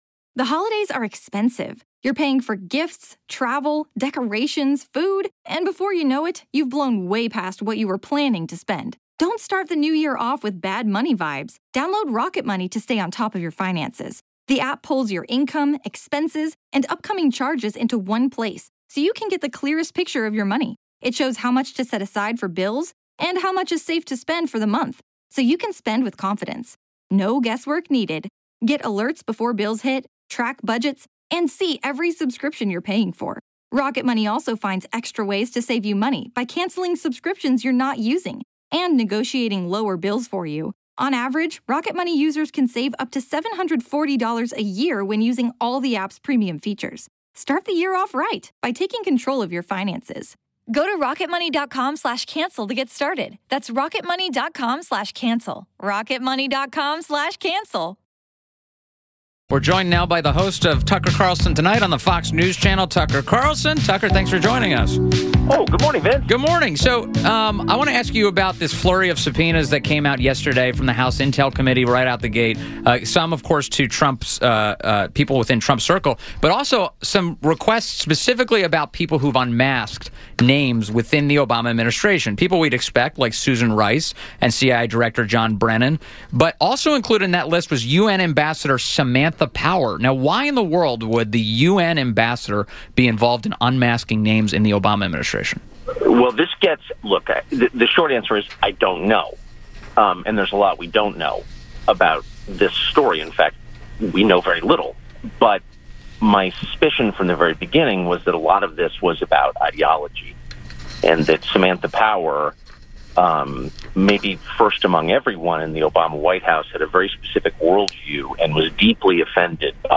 WMAL Interview - TUCKER CARLSON 06.01.17